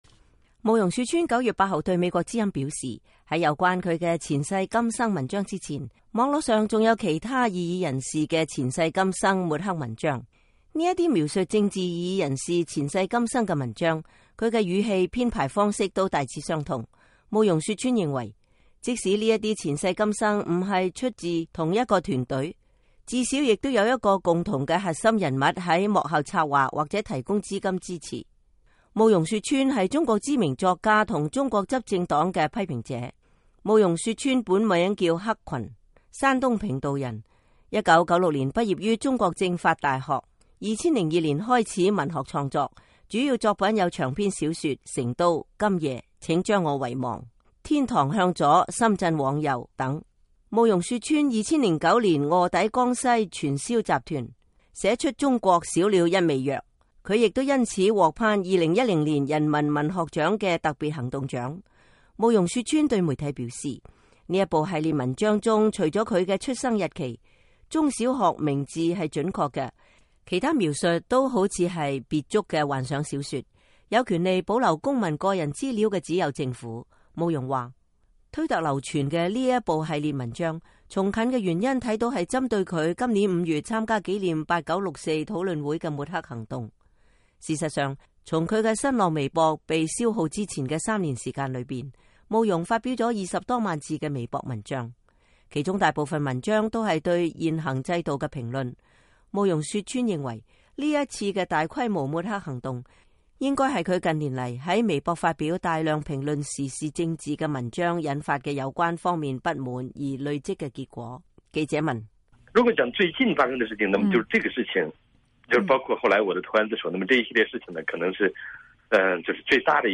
記者：我看了報導您覺得這是因為您參加了（今年）五月的家庭研討會，關於六四的。